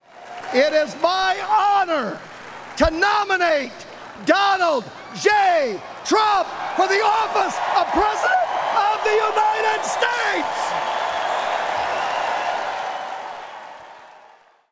Iowa G-O-P chairman Jeff Kaufmann was chosen to kick off the process with a nominating speech.